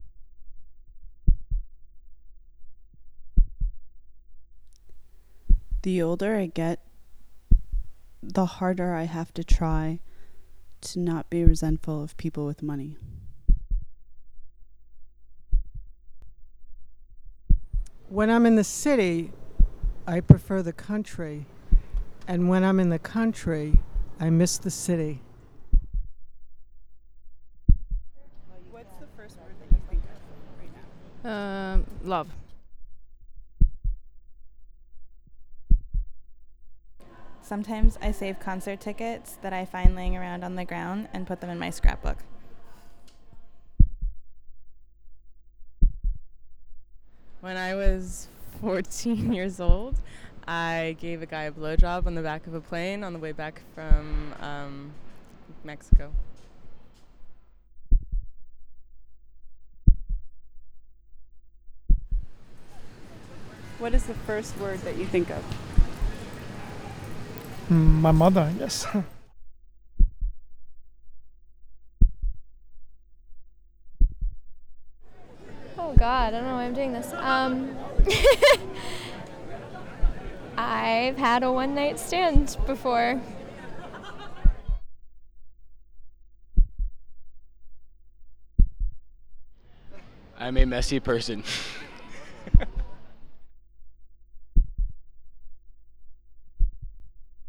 We went to Washington Square Park and got a few (dare I say) excellent recordings.